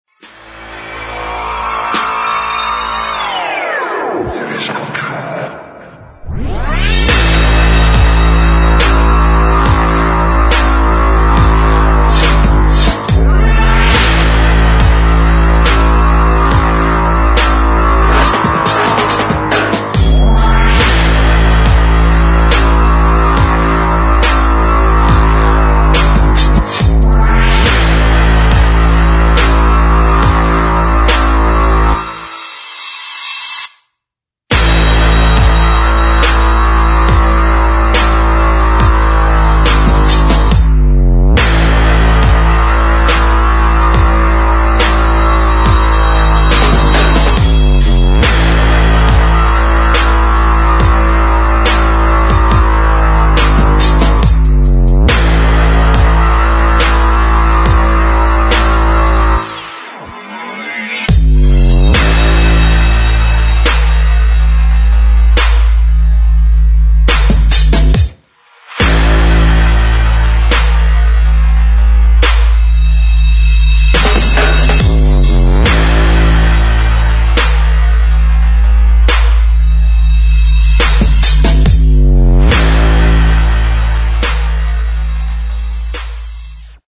[ DUBSTEP / GRIME ]